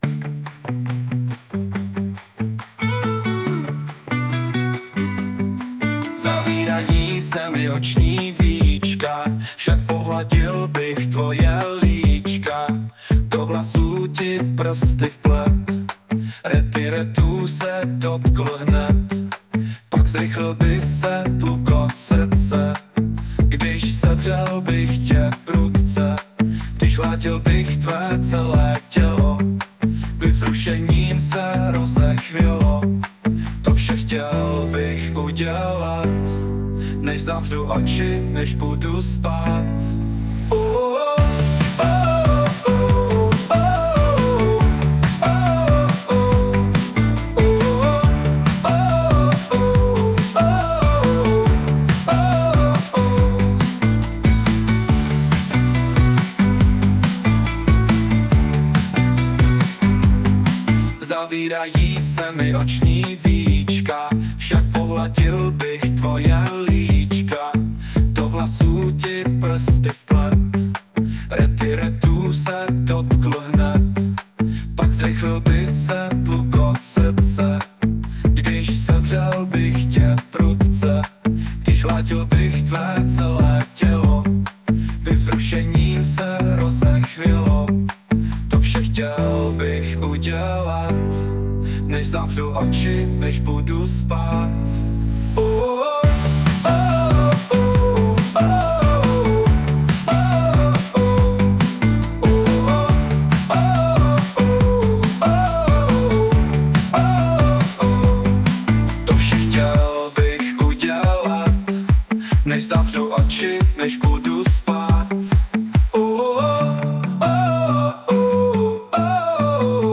hudba, zpěv: AI